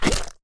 slimeattack1.ogg